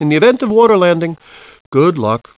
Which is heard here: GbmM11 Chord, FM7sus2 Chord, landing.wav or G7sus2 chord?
landing.wav